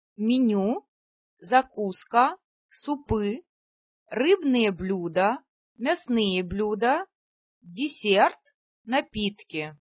[minju]Speisekarte